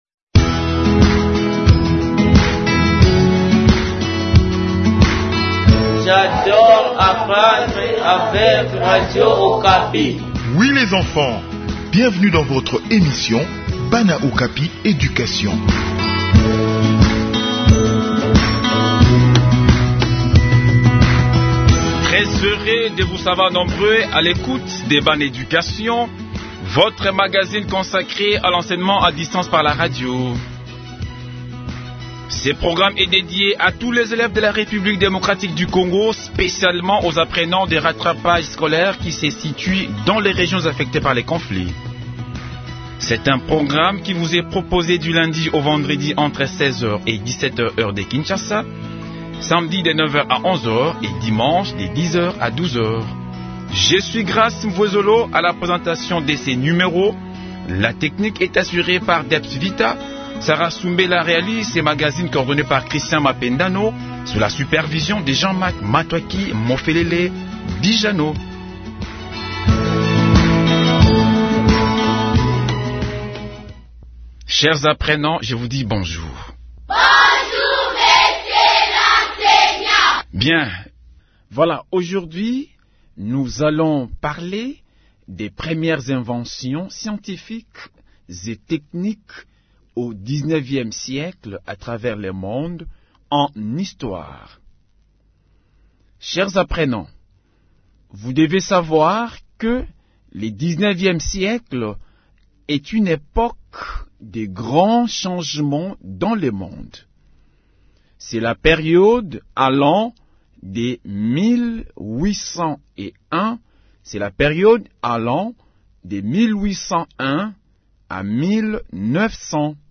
Réécoutez cette leçon pour mieux comprendre les racines du progrès.